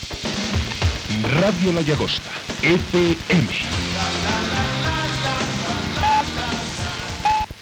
Identificació de l'emissora
FM